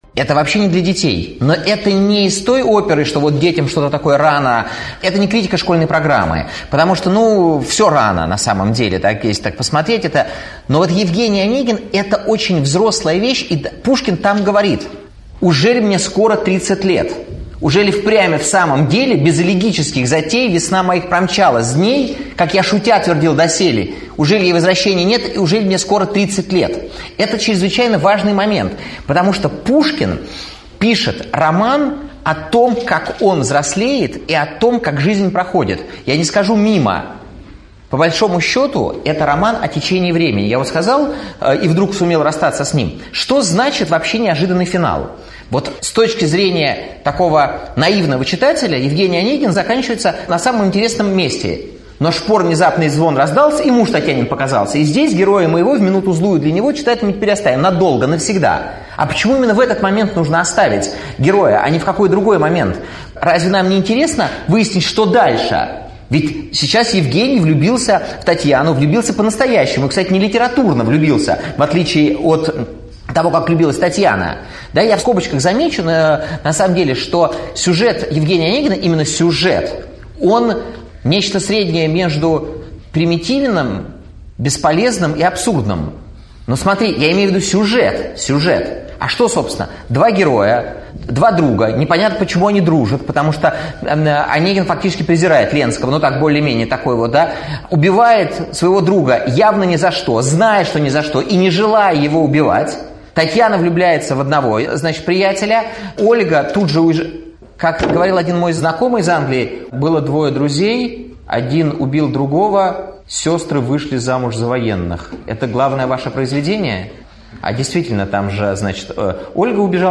Аудиокнига Пушкин. Евгений Онегин. Формула Блаженства | Библиотека аудиокниг